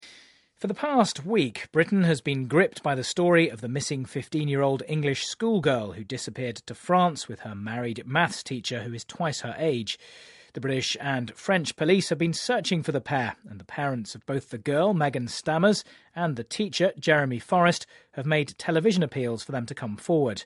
【英音模仿秀】现实版洛丽塔 听力文件下载—在线英语听力室